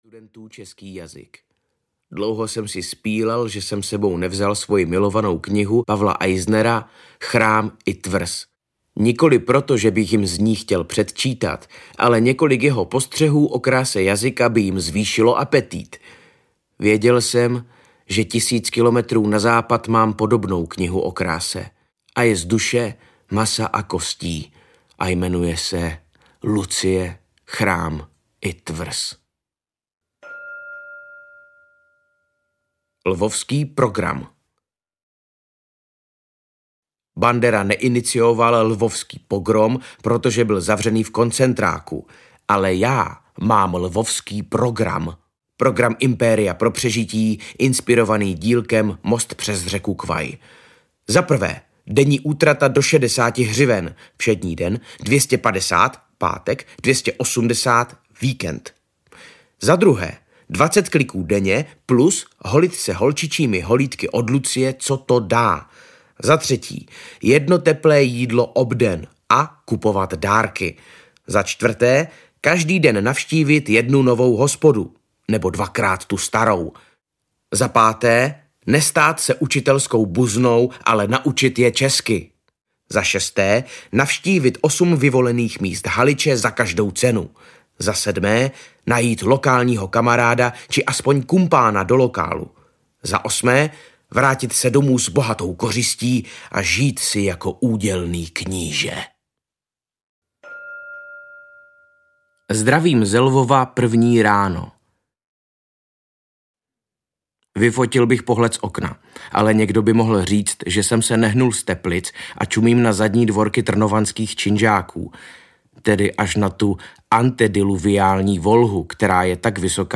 Ukázka z knihy
jazyk-ve-lvi-tlame-audiokniha